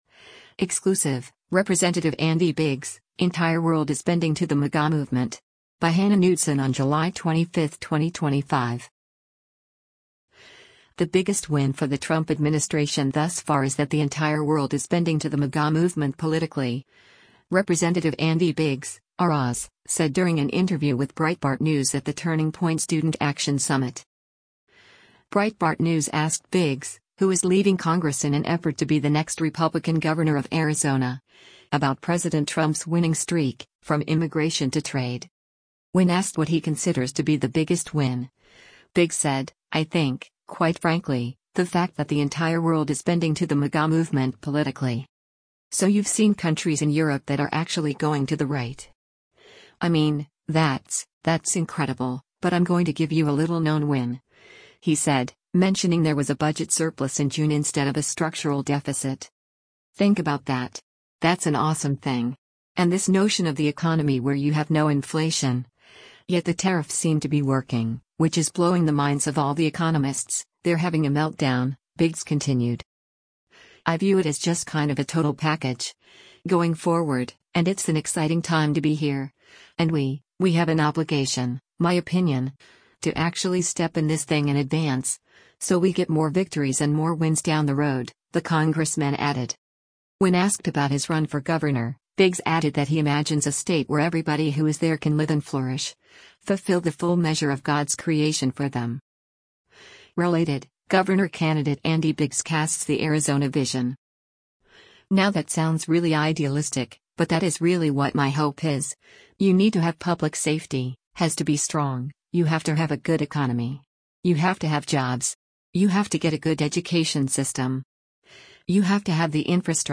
The biggest win for the Trump administration thus far is that the “entire world is bending to the MAGA movement politically,” Rep. Andy Biggs (R-AZ) said during an interview with Breitbart News at the Turning Point Student Action Summit.